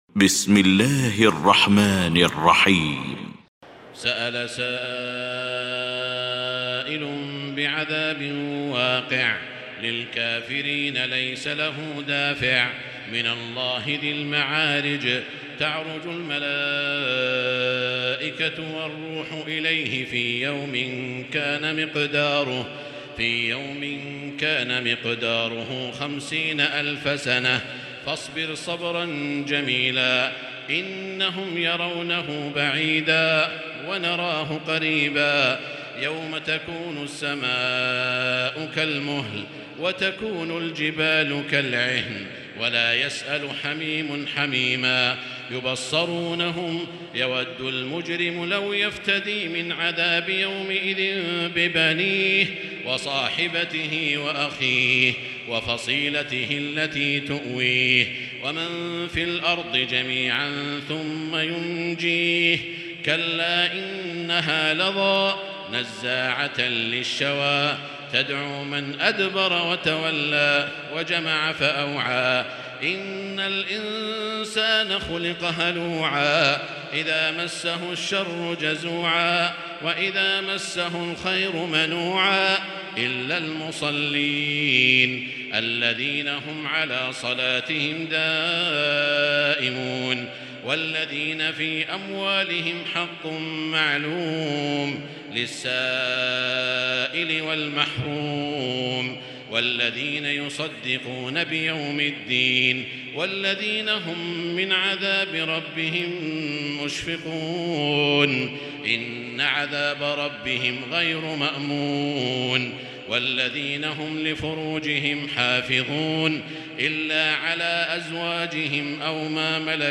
المكان: المسجد الحرام الشيخ: سعود الشريم سعود الشريم المعارج